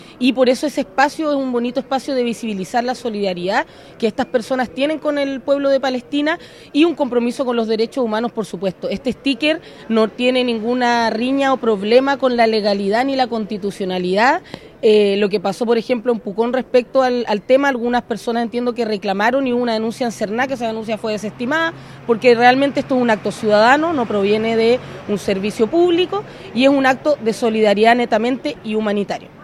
La consejera regional y miembro de la comunidad árabe en Valdivia, Camila Mattar, sostuvo que los locales comerciales son un espacio ideal para mostrar el mensaje, así como ocurrió en Pucón.